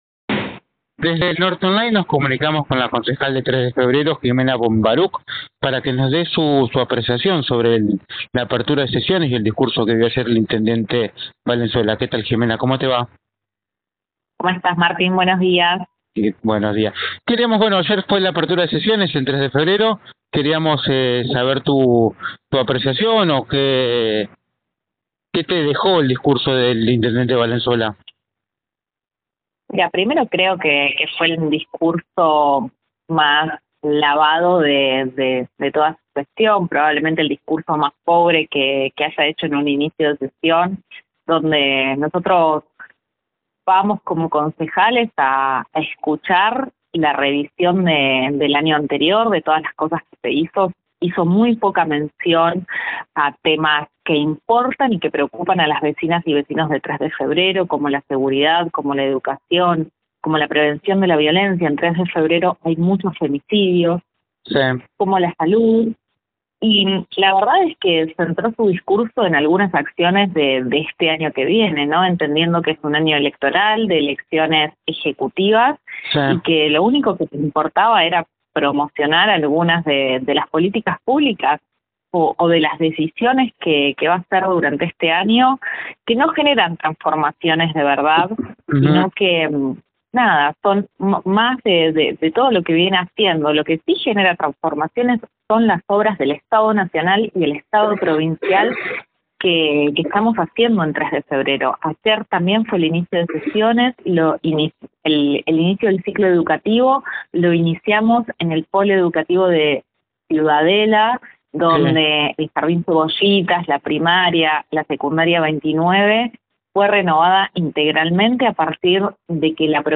La concejala del Frente Renovador en Tres de Febrero habló con NorteOnline y criticó la apertura de sesiones a cargo del intendente.